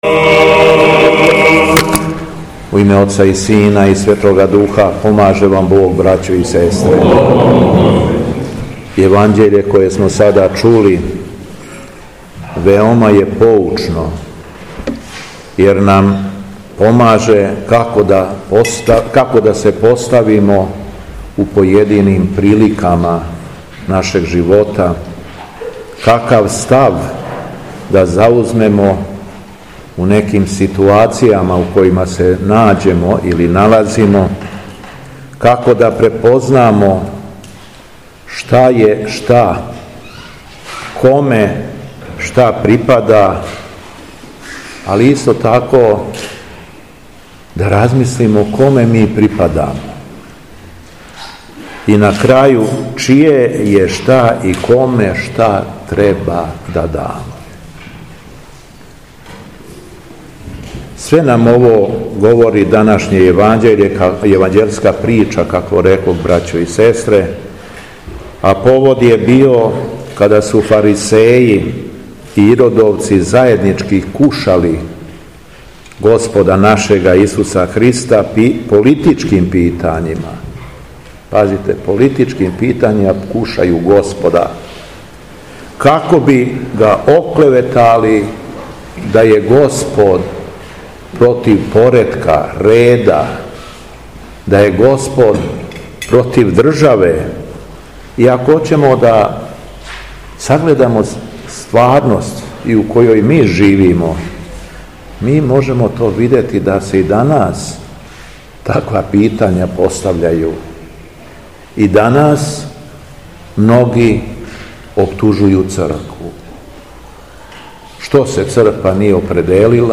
АРХИЈЕРЕЈСКА БЕСЕДА Поповић 06.09.2025.
У тринаесту суботу по Педесетници, 6. септембра, када наша Црква молитвено прославља светог свештеномученика Евтихија и свету мученицу Сиру, Његово Високопреосвештенство Митрополит Епархије шумадијске Г. Јован началствовао је евхаристијским сабрањем у храму Светог Георгија у Поповићу.